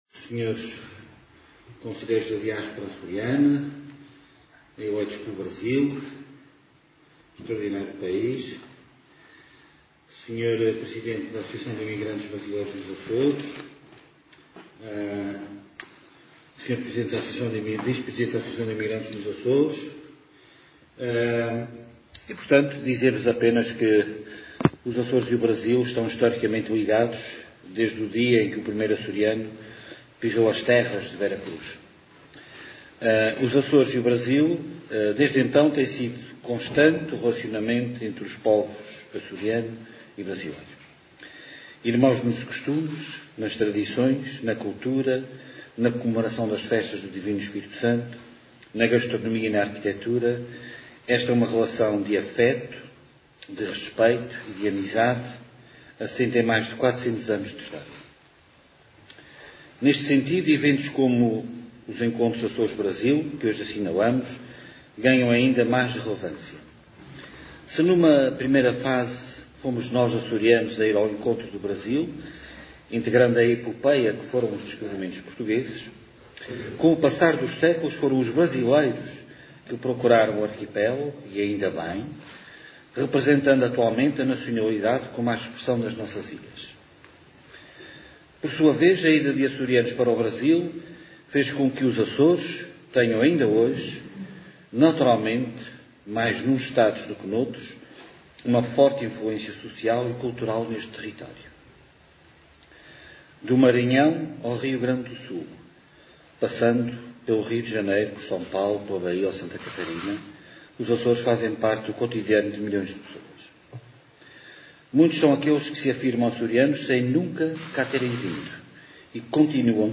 Artur Lima falava no II Encontro Açores-Brasil, que decorreu, em Angra do Heroísmo, e que contou com a presença dos Conselheiros da Diáspora Açoriana eleitos pelos círculos do Brasil e com associações de imigrantes.